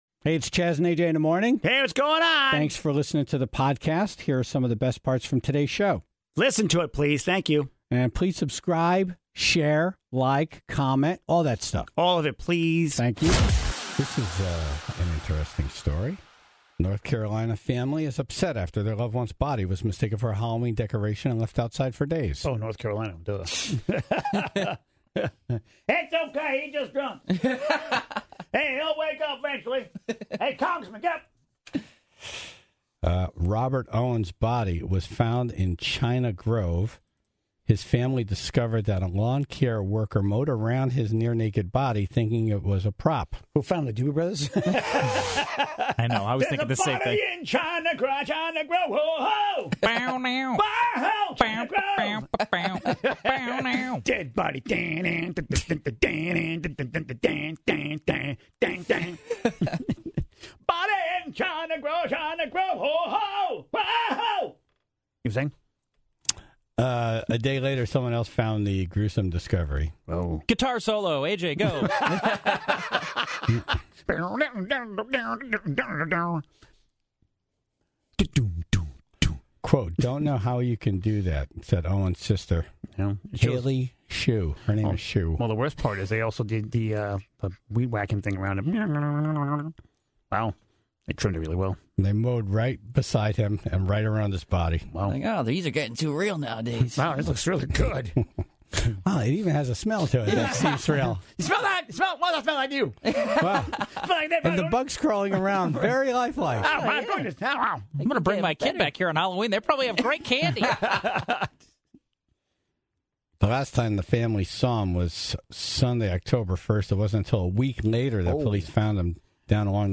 (3:39) The Tribe called in the dumb things they've seen co-workers do, like drive a forklift right through a wall into the CEO's office.